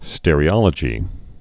(stĕrē-ŏlə-jē, stîr-)